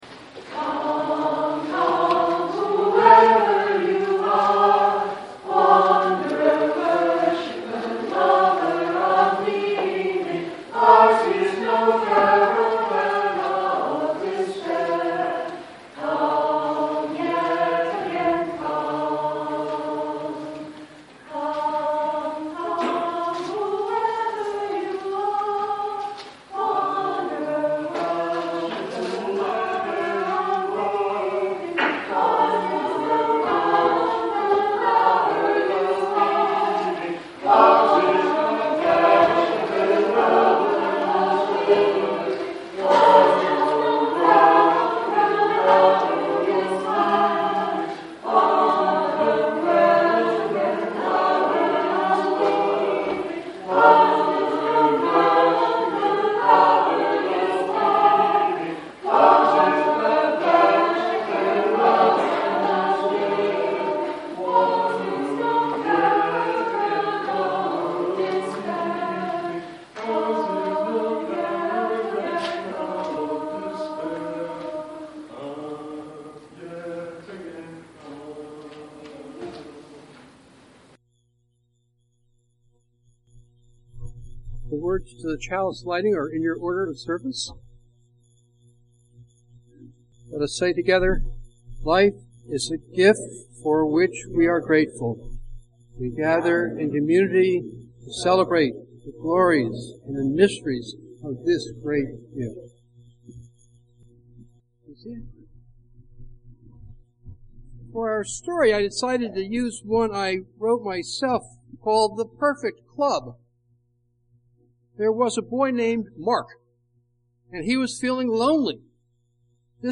With authentic Chinese music played on traditional instruments and songs by our children, and with the help of our own wonderful dragon, we will welcome in the new year. Chinese culture places great emphasis on respect for their elders.